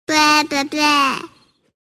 Category: Funny Ringtones